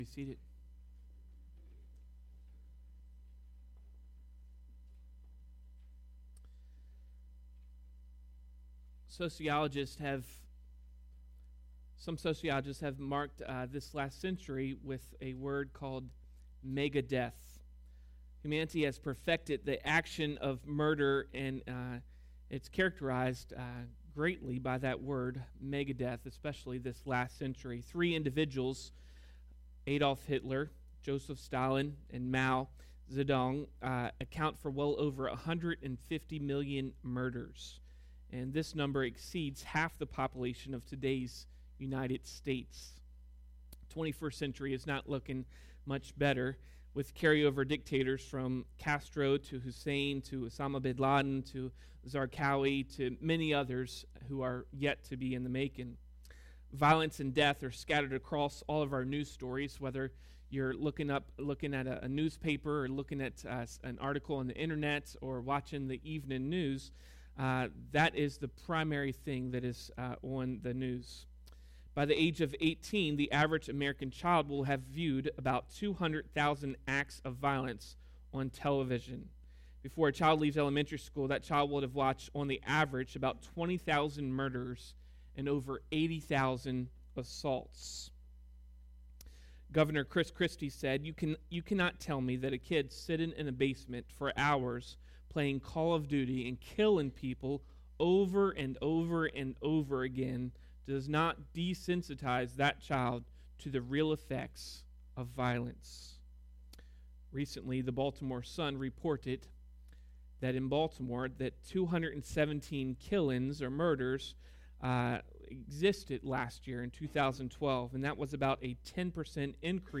sermon-feb-3rd-2013.mp3